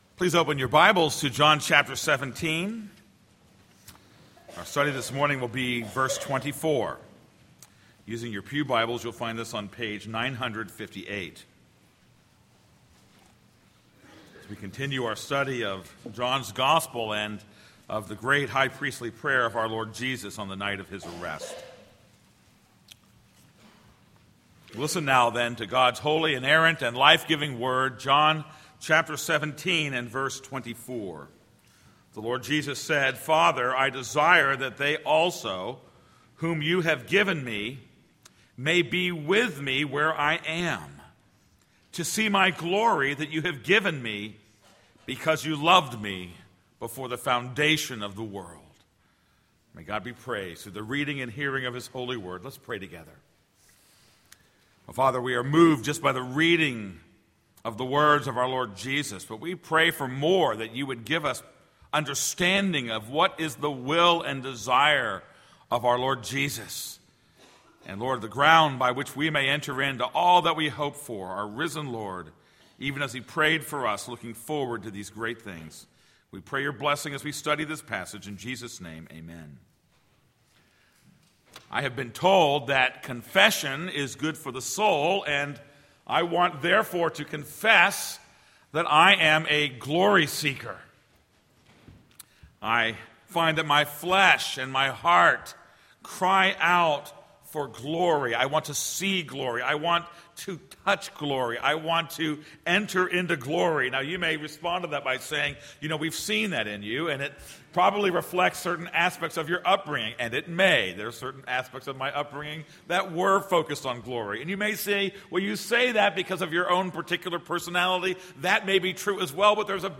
This is a sermon on John 17:24.